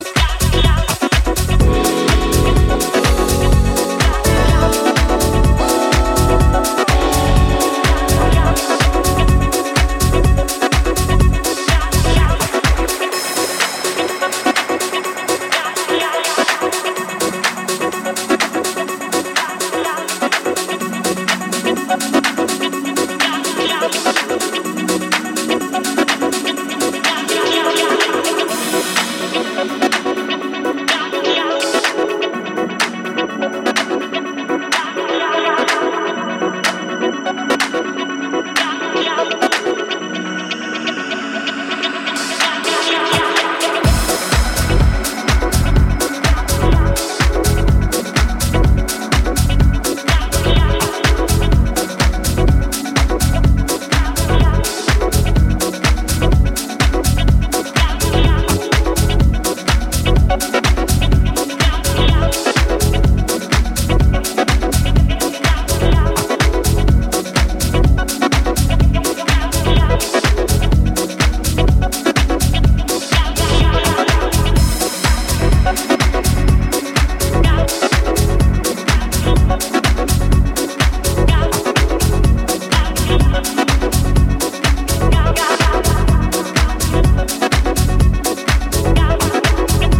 ジャズキーの効いたピュアなディープ・ハウス
原曲要素をダビーなエフェクトや低空飛行するベースラインであしらい、より没入度高く仕上げています！